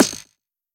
hitForth_Far2.wav